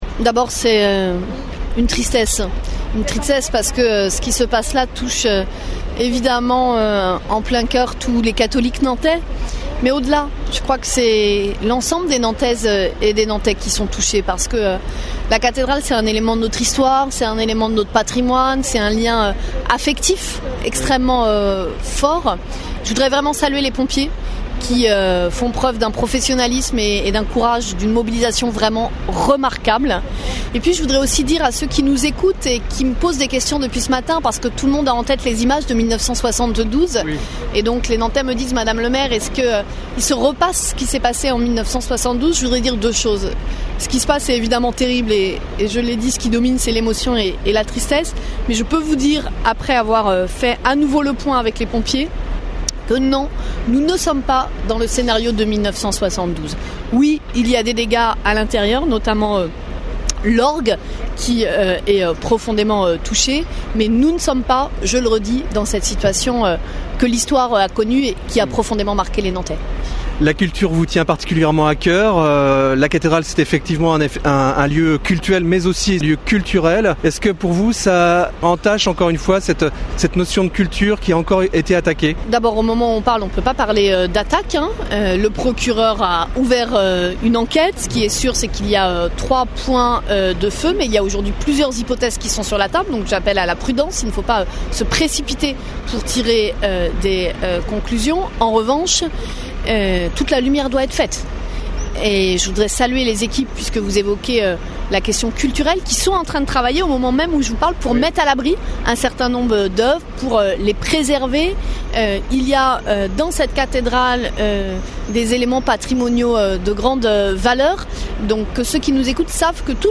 Réaction de Johanna Rolland, maire de Nantes.